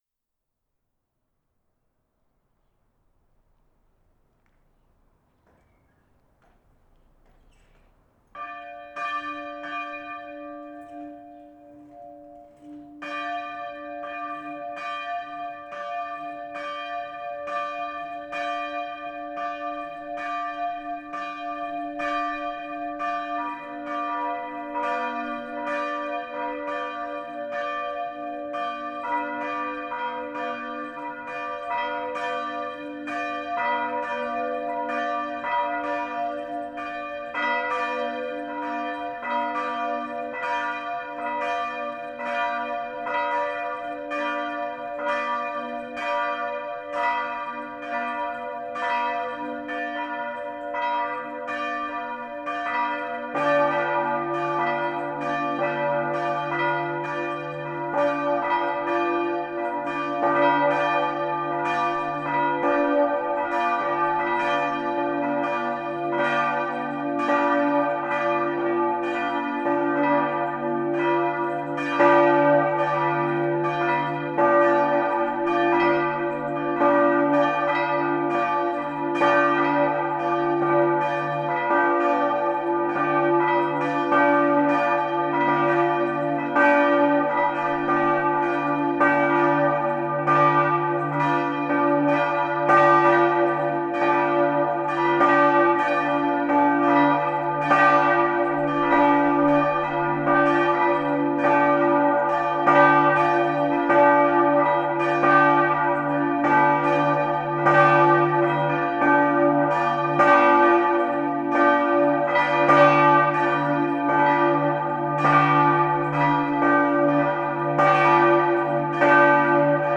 Le clocher abrite trois cloches.
2 N.C. Breton 1817 78 ~300 Si 3
Ci-dessous, voici toutefois un des enregistrements extérieurs réalisés. On notera diverses pollutions sonores imprévues…
crets-en-belledonne-st-pierre.mp3